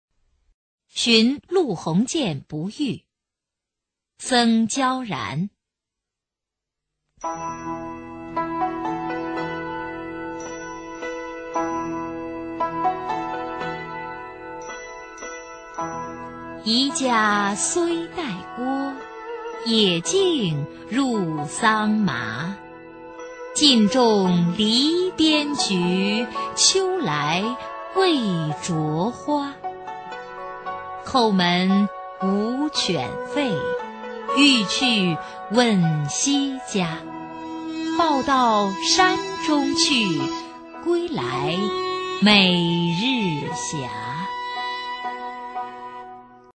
[隋唐诗词诵读]僧皎然-寻陆鸿渐不遇 配乐诗朗诵